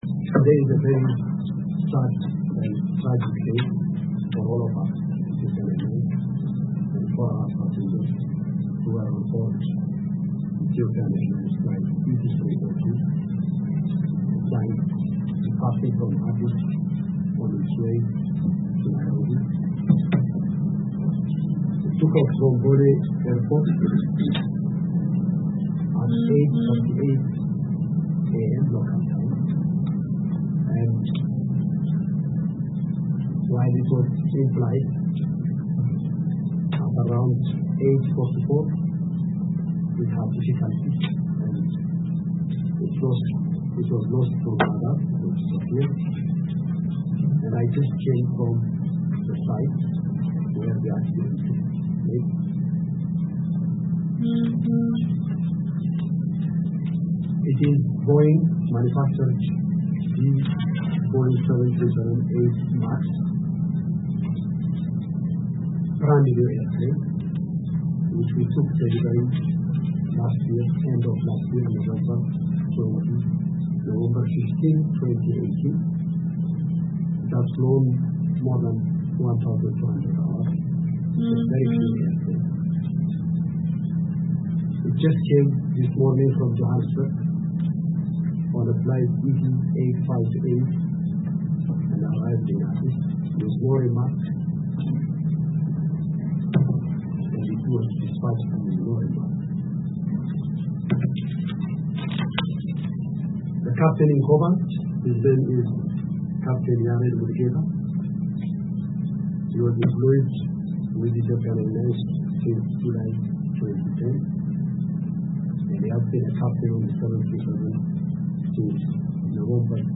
አቶ ተወልደ ገብረማርያም፤ የኢትዮጵያ አየር መንገድ ዋና ሥራ አስፈፃሚ አቶ ተወልደ ገብረማርያም ጋዜጣዊ መግለጫ /እንግሊዝኛ/